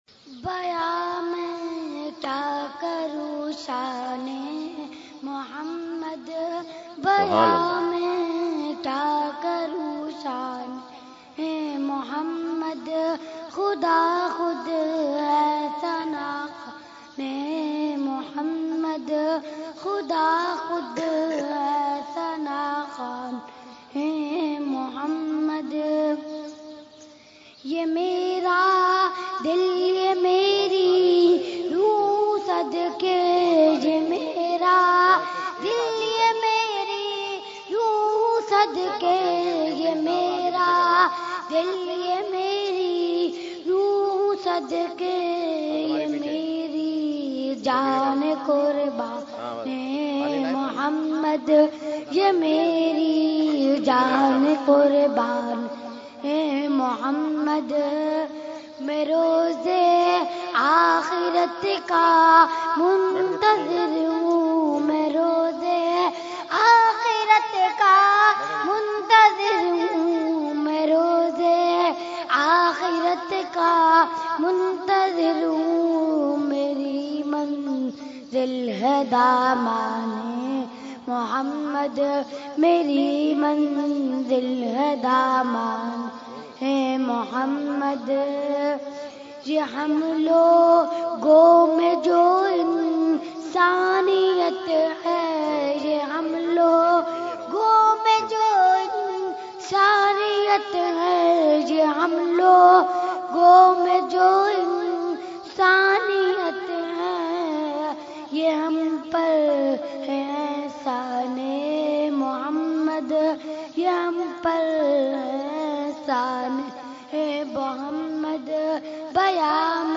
Category : Naat | Language : UrduEvent : Urs Qutbe Rabbani 2016